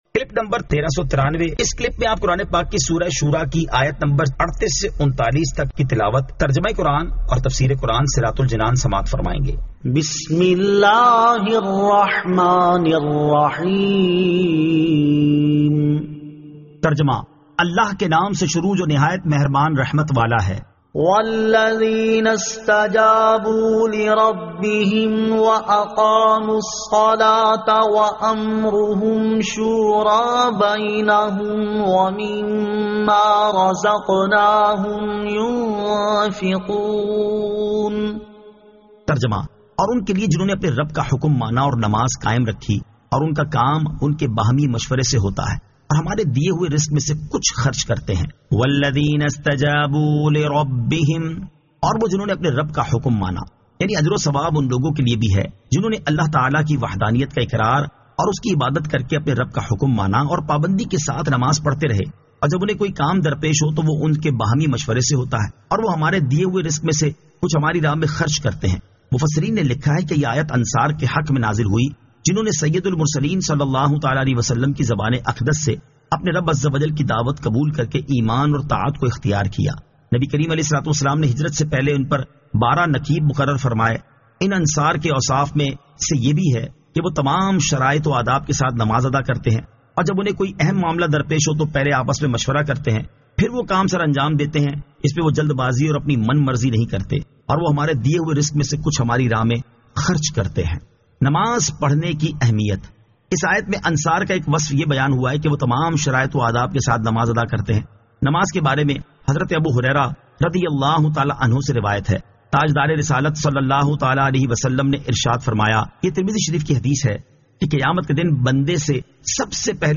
Surah Ash-Shuraa 38 To 39 Tilawat , Tarjama , Tafseer